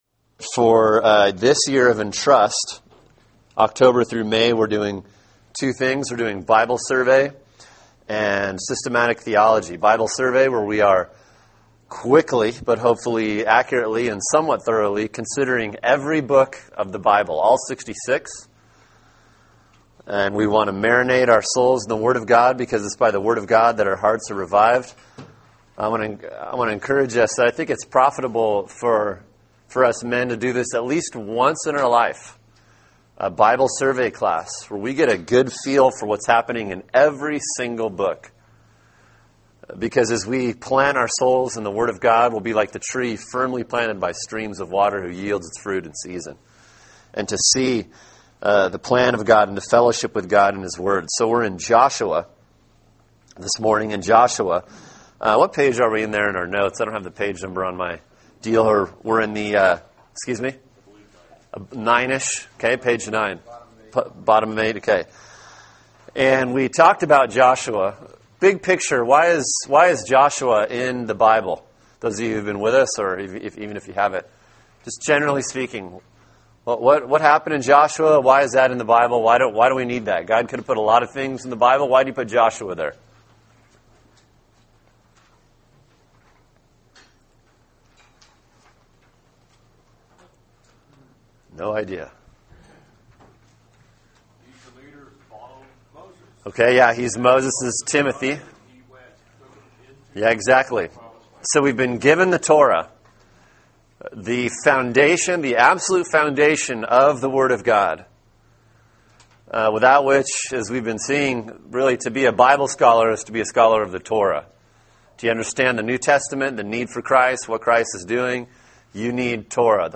Old Testament Survey Lesson 5: Judges, Ruth